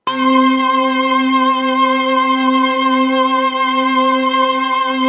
描述：通过Behringer调音台采样到MPC 1000。它听起来一点也不像风的部分，因此被称为Broken Wind。
标签： 模拟 多样品 合成器 虚拟模
声道立体声